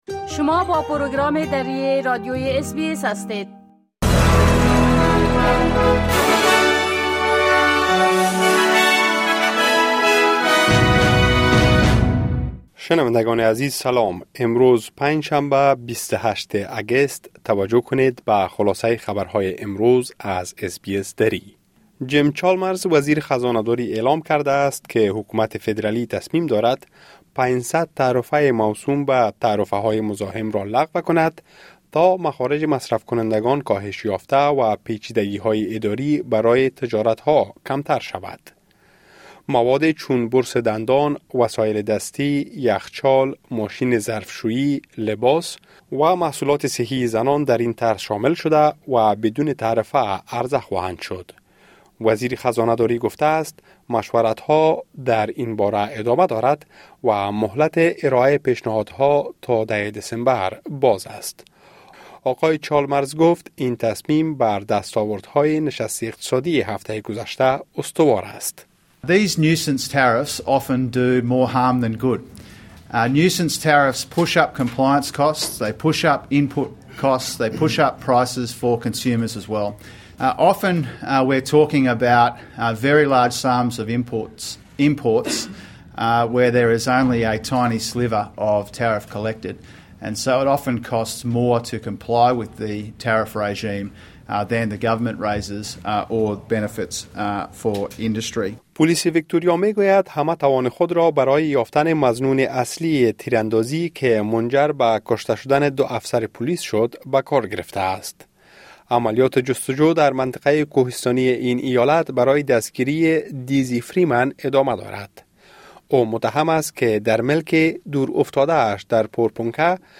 خلاصه مهمترين خبرهای روز از بخش درى راديوى اس‌بى‌اس| ۲۸ اگست